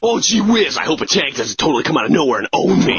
Worms speechbanks
Comeonthen.wav